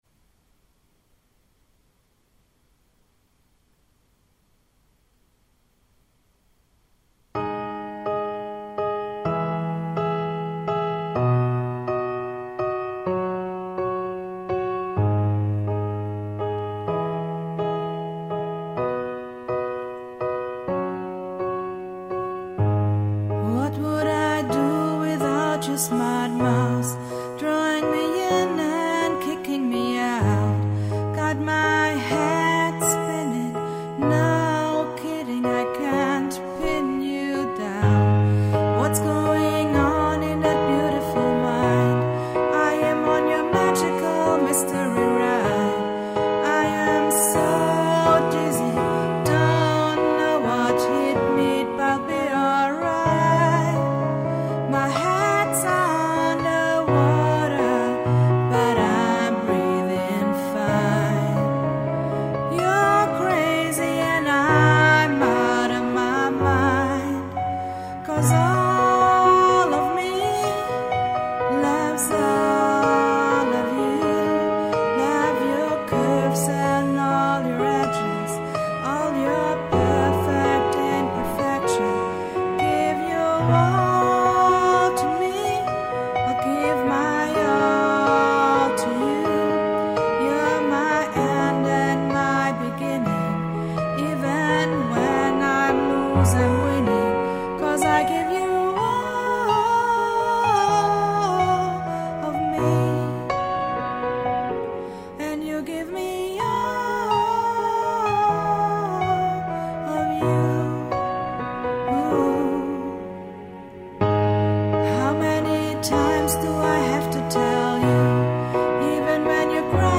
• Coverband
• Sänger/in